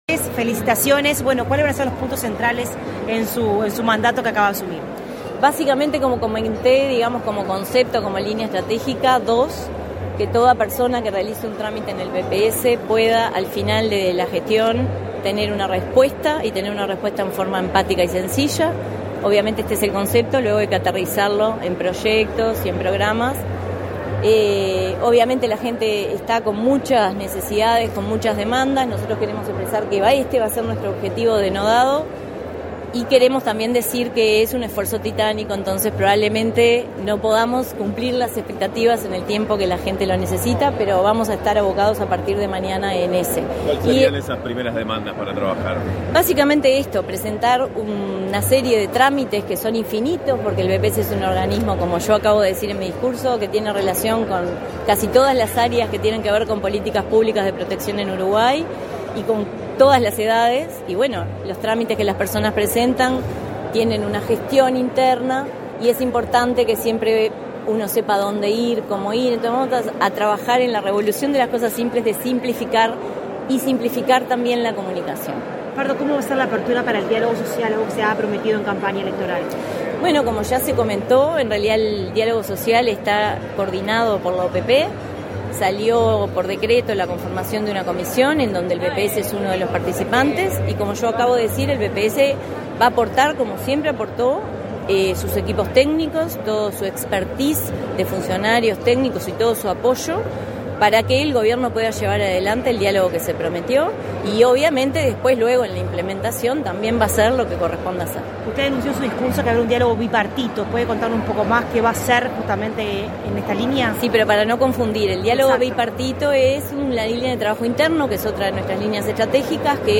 Declaraciones a la prensa de la presidenta del BPS, Jimena Pardo
Declaraciones a la prensa de la presidenta del BPS, Jimena Pardo 27/03/2025 Compartir Facebook X Copiar enlace WhatsApp LinkedIn Este 27 de marzo, asumieron las autoridades del Banco de Previsión Social (BPS). Su presidenta Jimena Pardo, tras el evento, realizó declaraciones a la prensa.